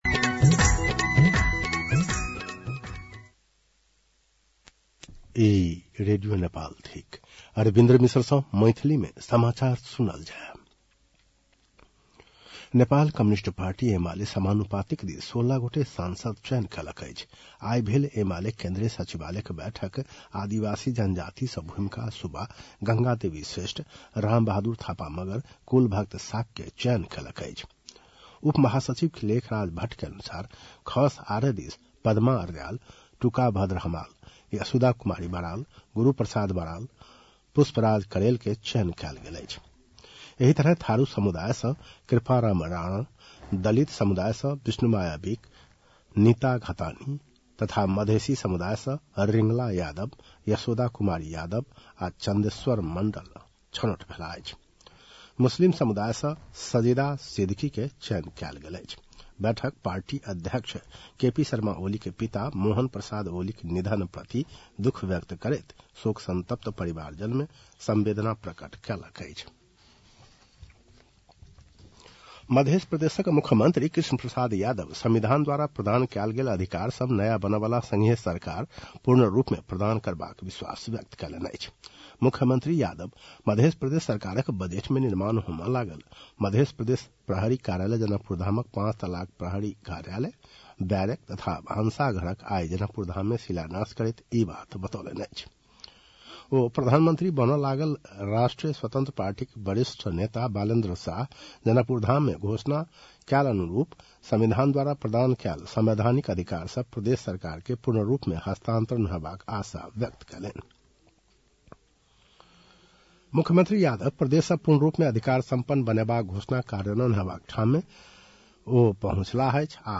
मैथिली भाषामा समाचार : ३० फागुन , २०८२
6.-pm-maithali-news-1-3.mp3